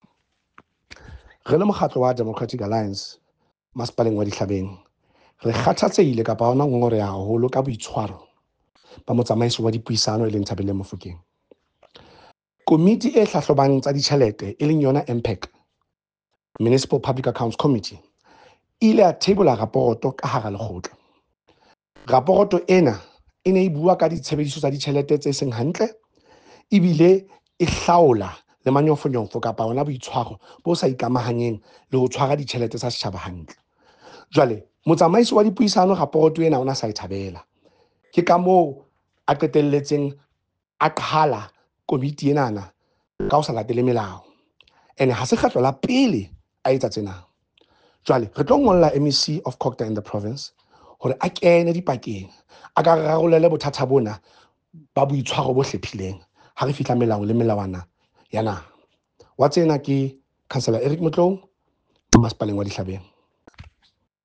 Sesotho soundbites by Cllr Eric Motloung